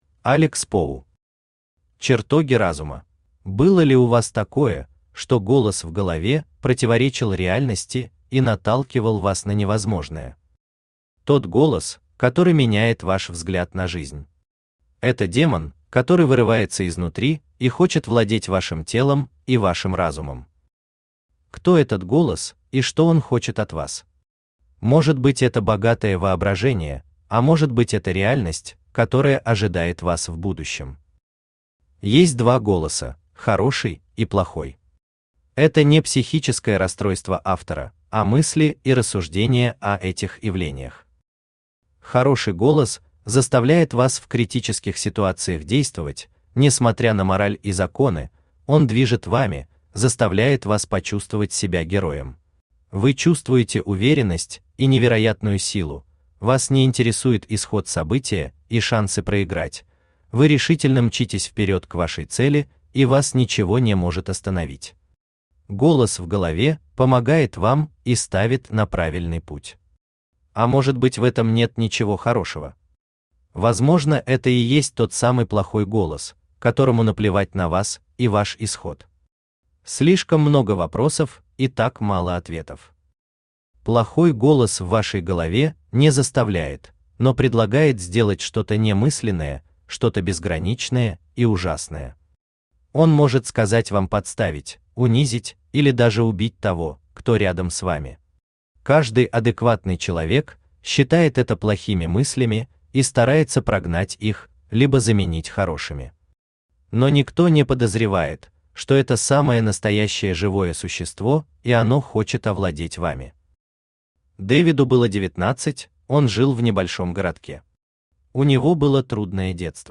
Аудиокнига Чертоги разума | Библиотека аудиокниг
Читает аудиокнигу Авточтец ЛитРес